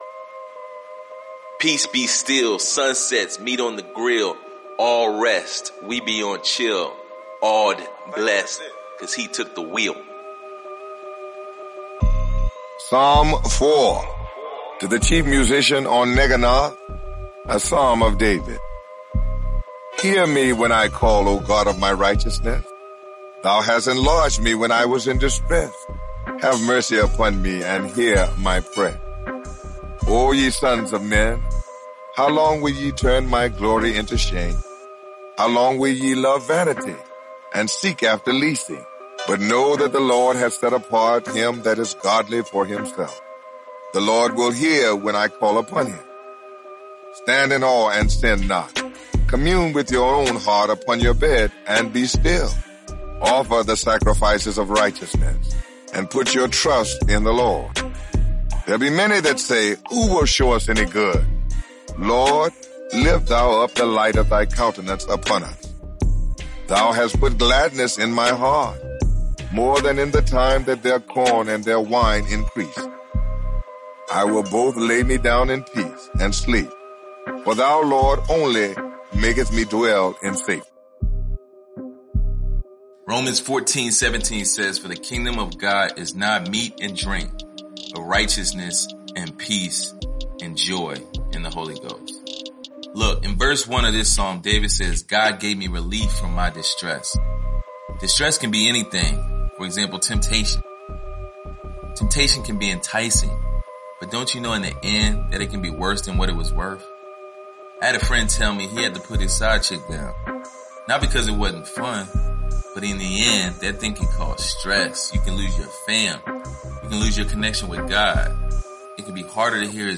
Experience the Psalms in a fresh new way! This narrated devotional provides encouraging insight over original music that injects hope, faith, wisdom, inspiration, and so much more through the Psalms!